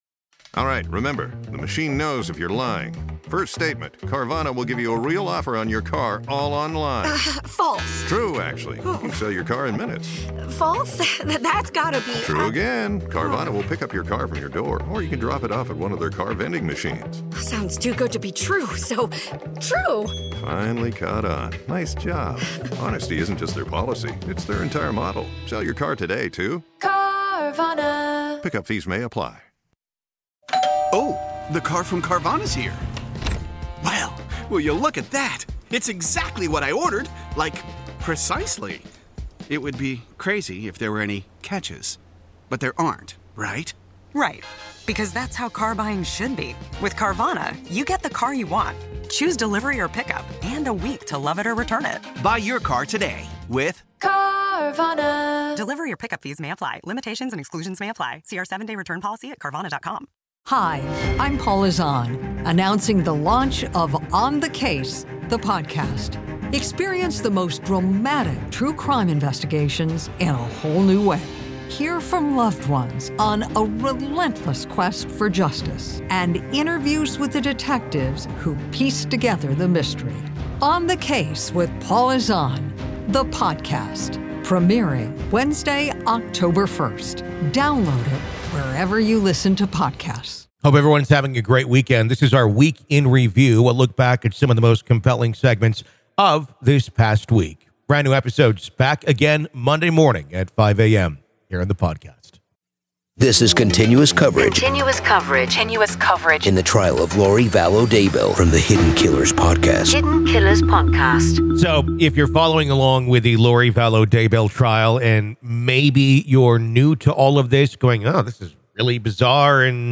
Welcome to "The Week In Review," a riveting journey that takes you back through the most captivating interviews, gripping updates, and electrifying court audio from the cases that have captured our attention.